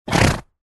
Звуки ослов
Фырканье осла